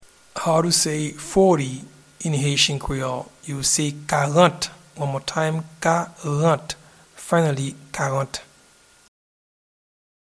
Pronunciation and Transcript:
Forty-in-Haitian-Creole-Karant.mp3